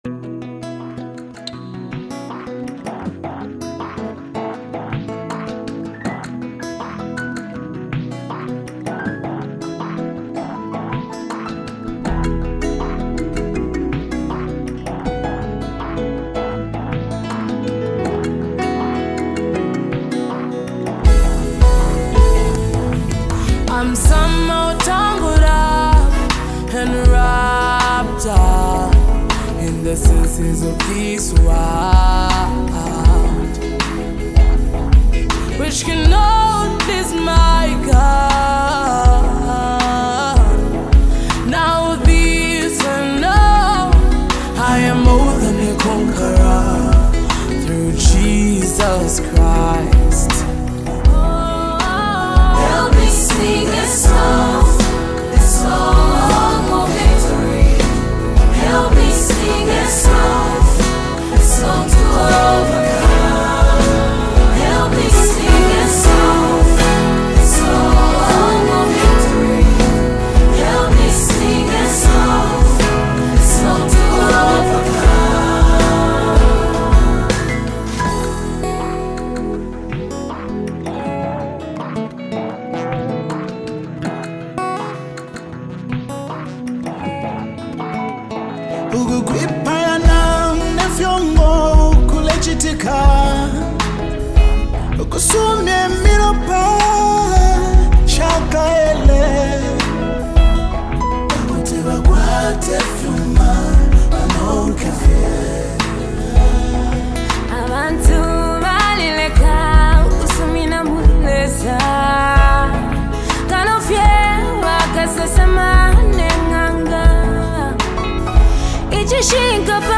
Zambian Gospel ministers
spirit filled tune